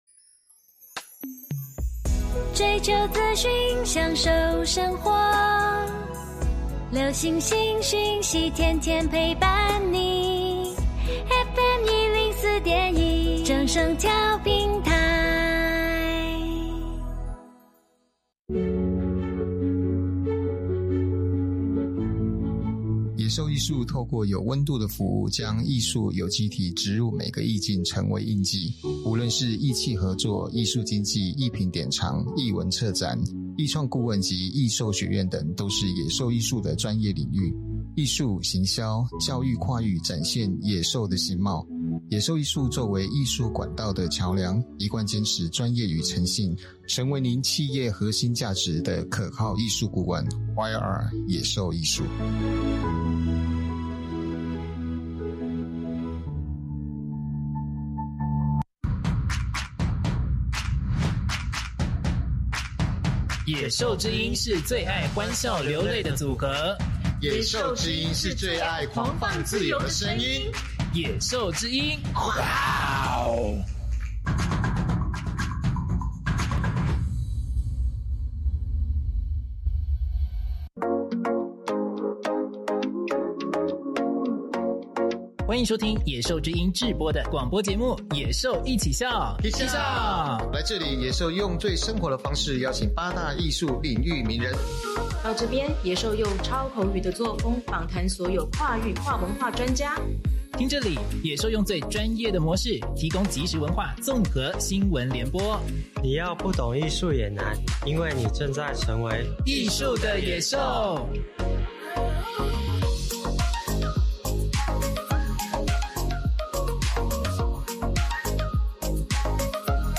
Royalty Free Music/ SounDotCom